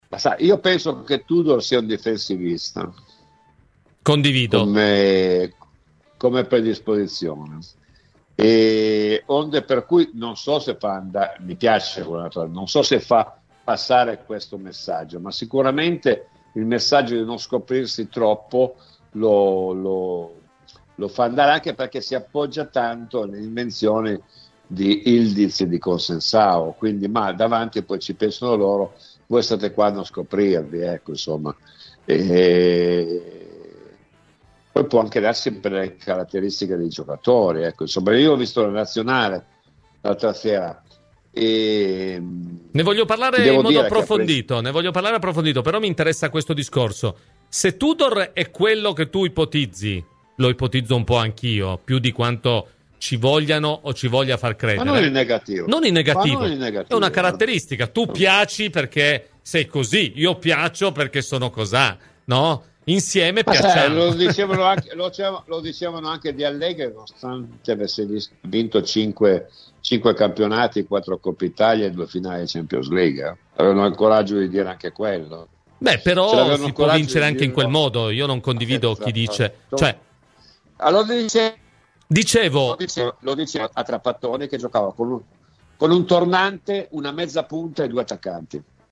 L’ex centrocampista bianconero, ospite a Cose di Calcio su Radio Bianconera, ha parlato del tecnico della Juventus Igor Tudor.